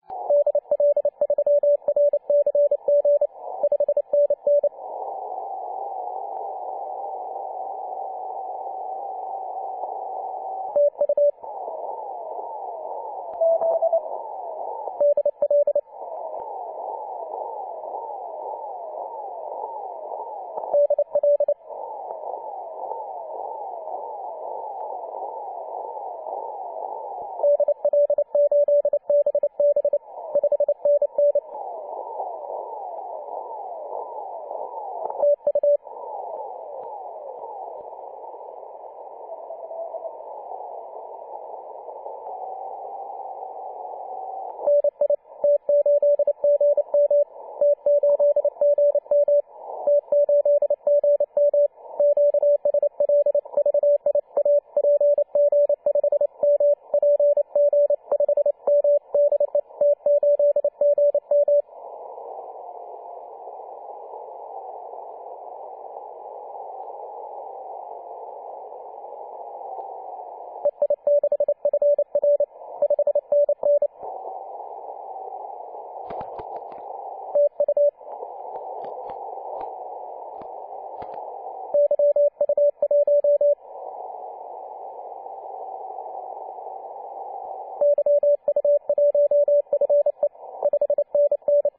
14.023MHz CW